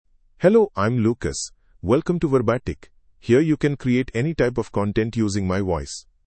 Lucas — Male English (India) AI Voice | TTS, Voice Cloning & Video | Verbatik AI
Lucas is a male AI voice for English (India).
Voice sample
Male
Lucas delivers clear pronunciation with authentic India English intonation, making your content sound professionally produced.